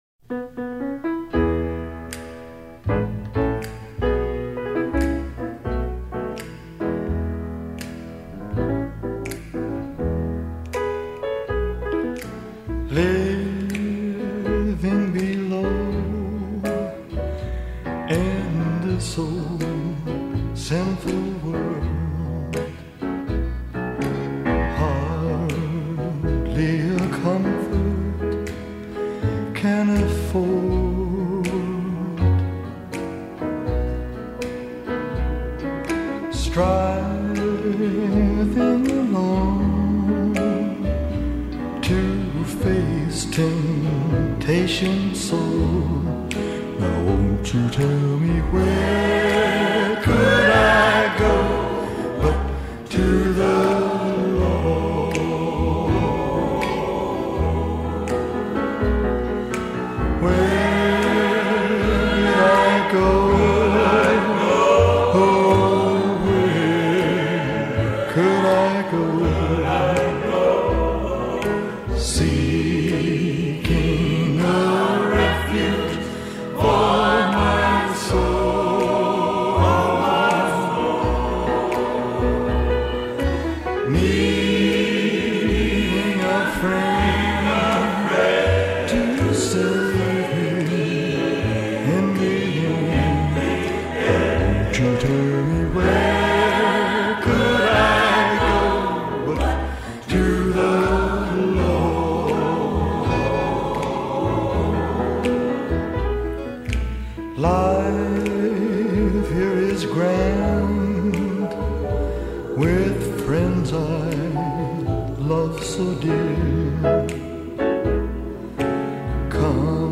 音乐类型：西洋音乐